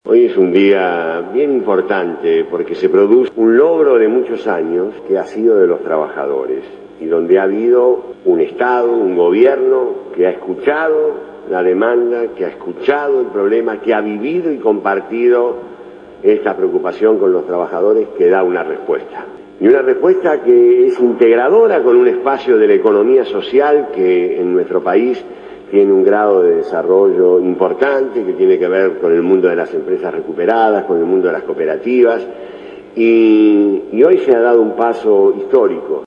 En una conferencia de prensa realizada en Casa de Gobierno, se anunció la promulgación de la reforma a la ley de quiebras.